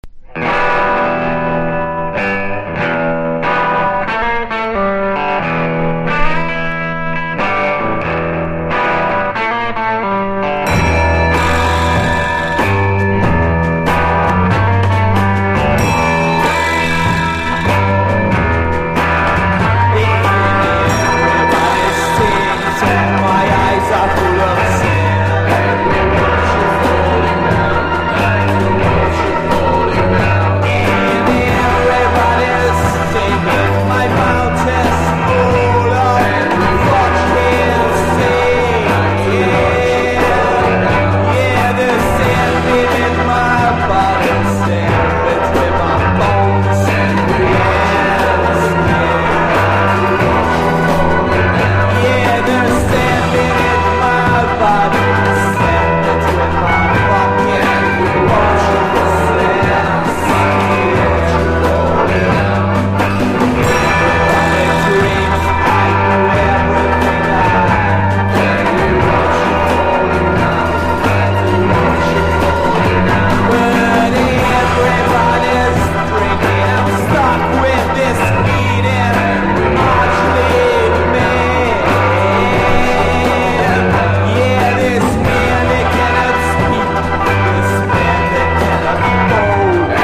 GARAGE ROCK# POST PUNK (90-20’s)